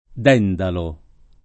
[ d $ ndalo ]